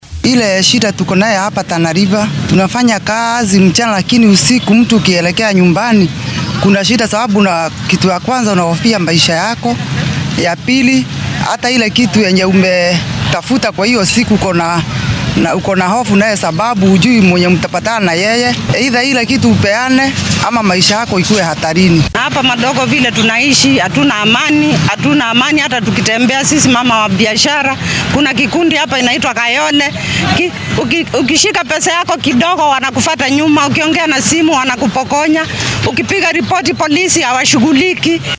Qaar ka mid ah shacabka Madogo oo warbaahinta la hadlay ayaa dareenkooda sidatan u muujiyay.